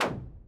EXPLOSION_Subtle_Bright_Swoosh_stereo.wav